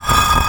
brick_m1.wav